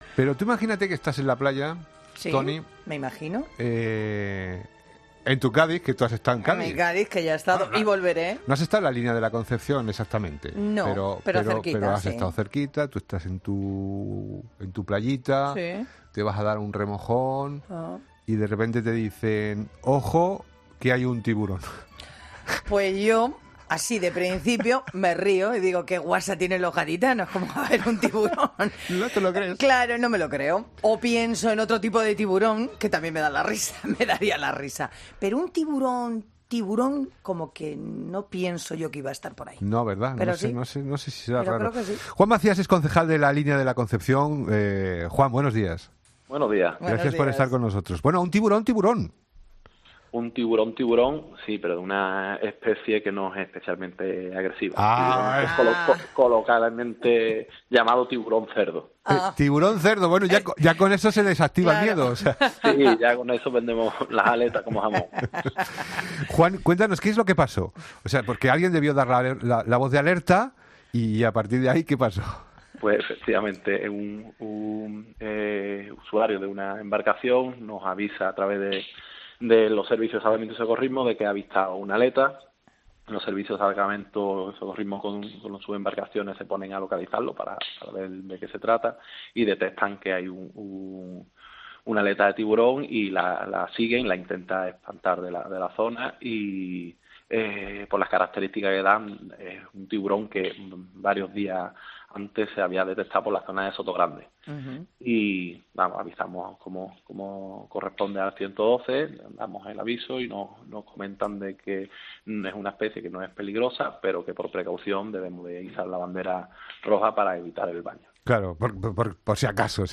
ESCUCHA LA ENTREVISTA COMPLETA | Juan Macías, concejal de Playas de La Línea de la Concepción